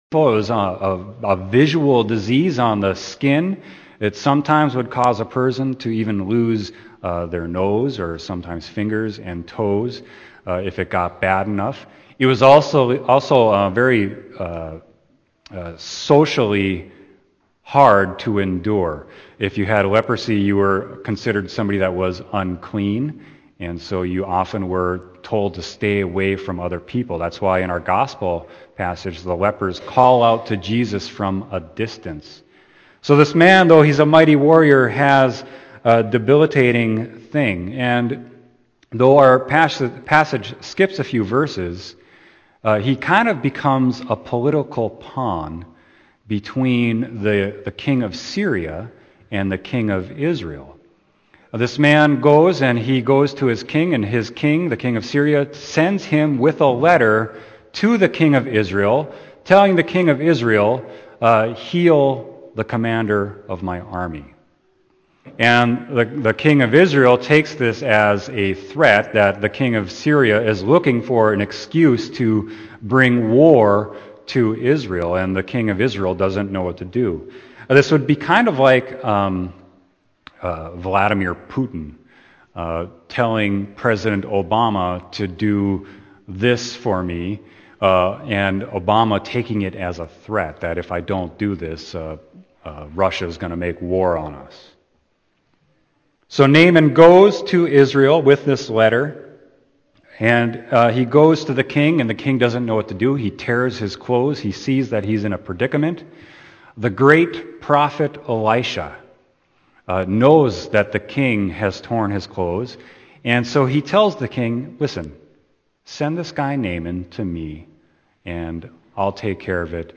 Sermon: 2-kings-5-1-15